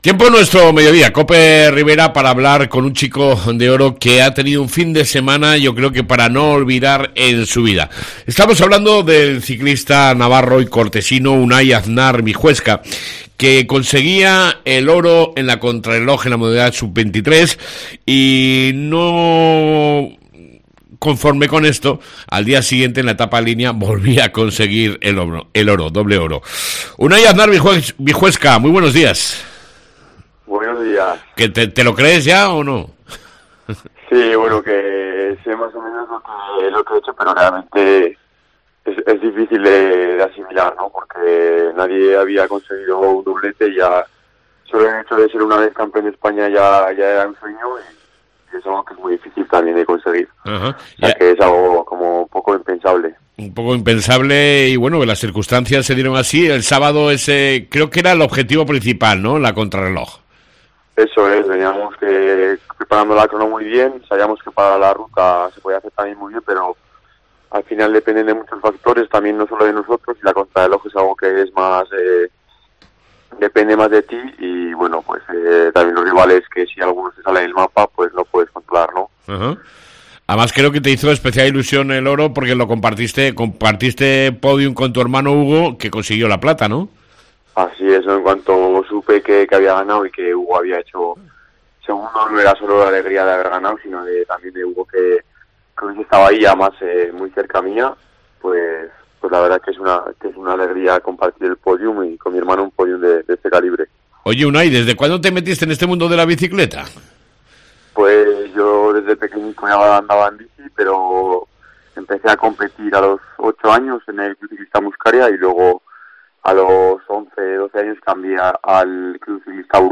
ENTREVISTA CON EL CICCISTA DE CORTES